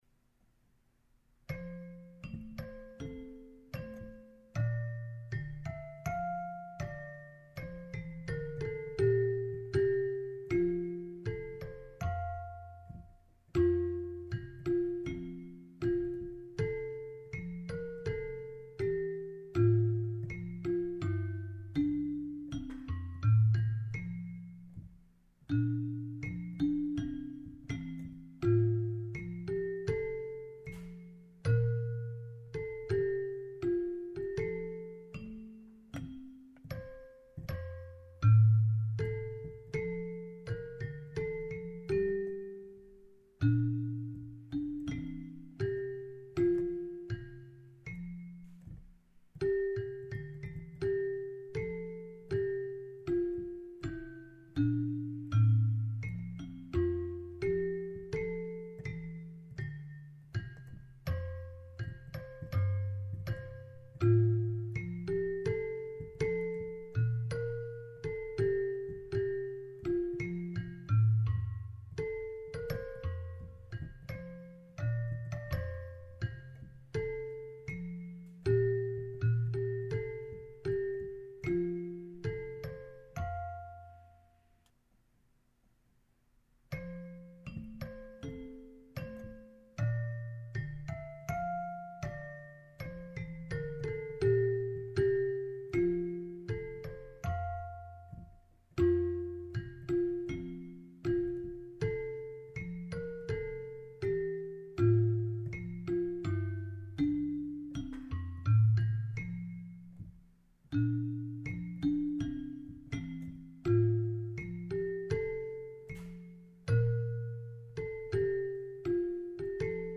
■校 歌
校歌オルゴール.mp3